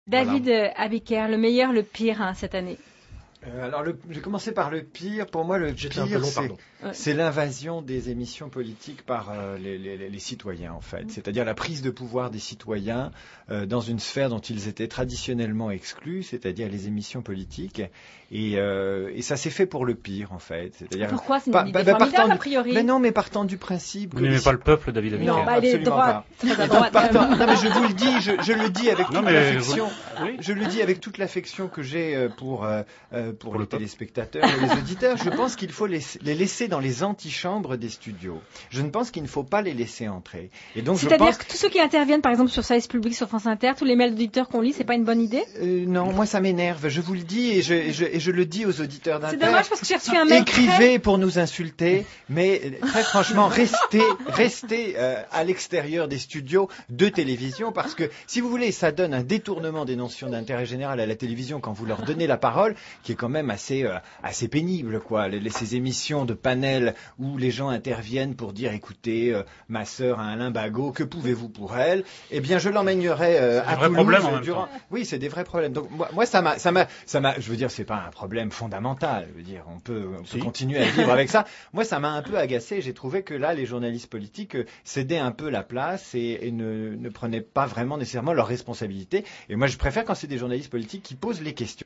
Emission « J’ai mes sources », animée par Colombe Schneck sur France Inter, 29 juin 2007. Thème du jour : « Le meilleur du pire de la saison 2006-2007 ».